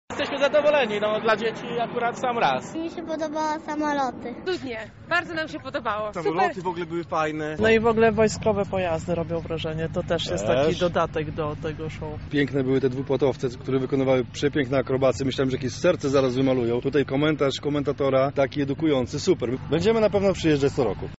SONDA
airshowsonda.mp3